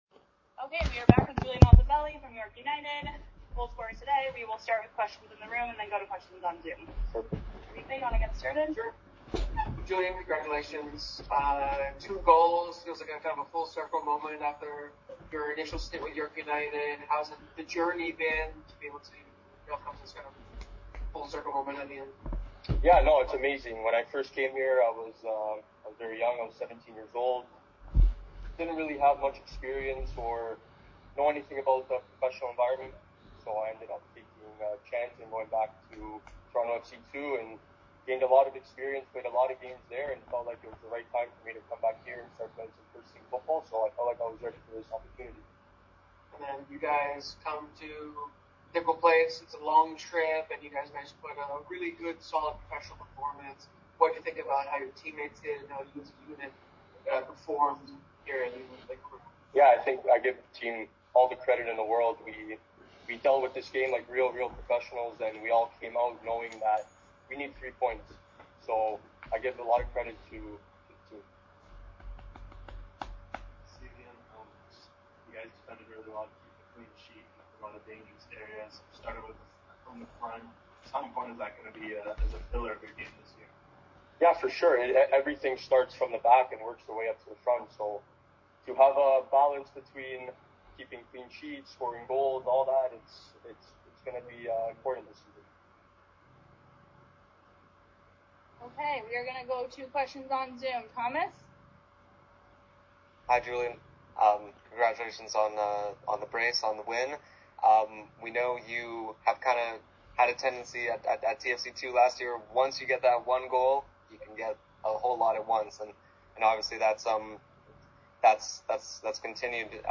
April 6, 2025...Post Game press conference audio after the CPL game between Vancouver FC vs York United FC
at Langley Events Centre the Township of Langley BC.